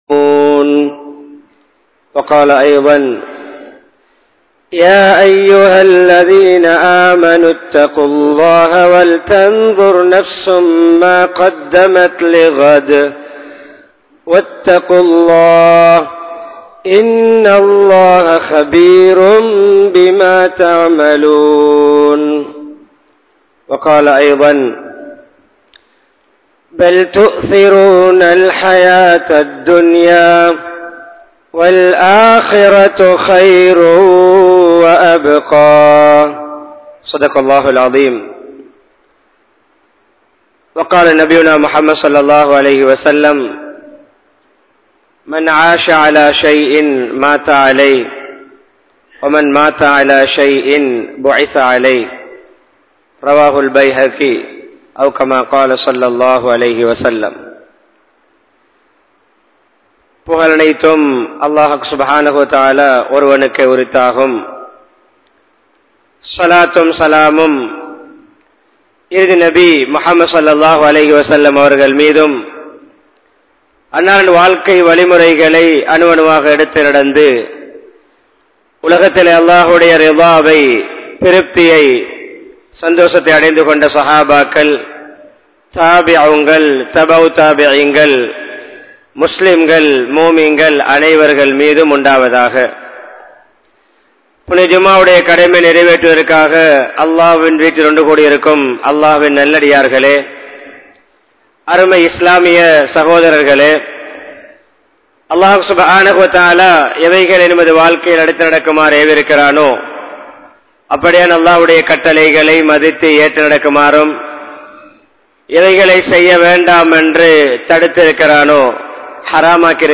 மரணத்தின் நிலை | Audio Bayans | All Ceylon Muslim Youth Community | Addalaichenai